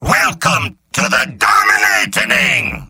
Robot-filtered lines from MvM. This is an audio clip from the game Team Fortress 2 .
{{AudioTF2}} Category:Demoman Robot audio responses You cannot overwrite this file.